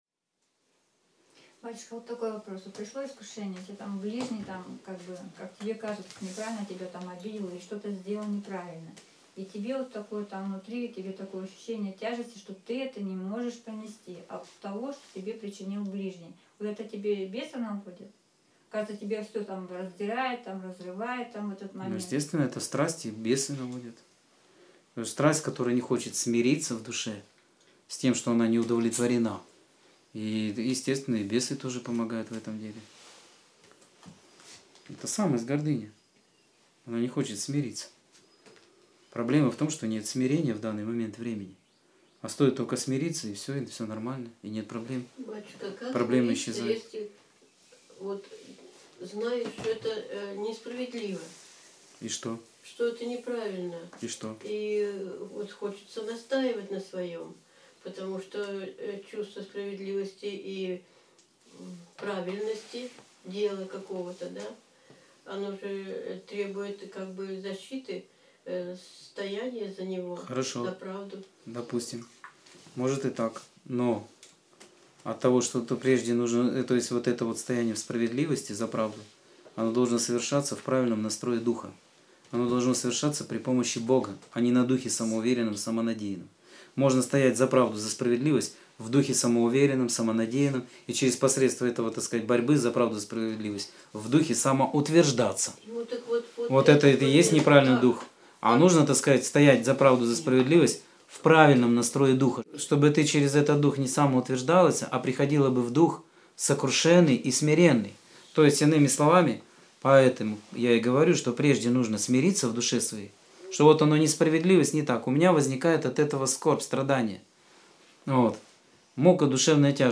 Скайп-беседа 4.10.2014